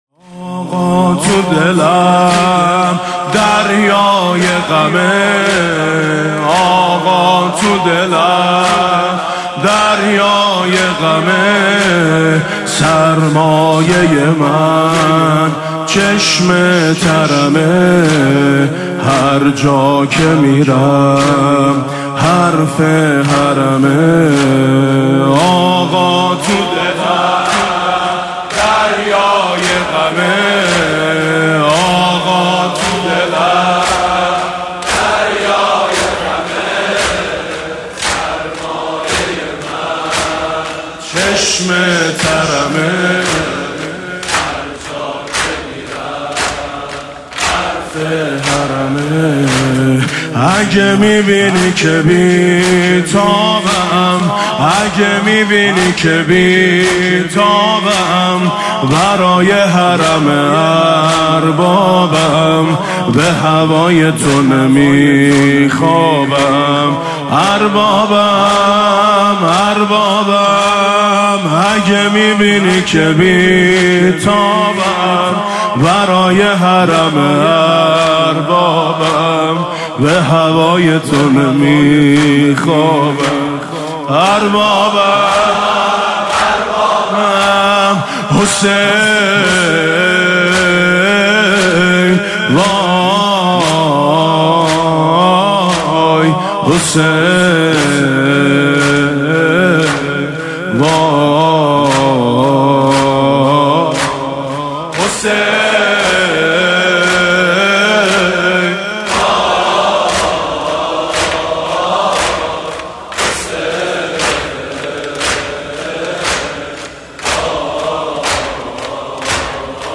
زمینه - هرجا که میرم حرف حرمه